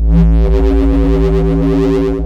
Desecrated bass hit 18.wav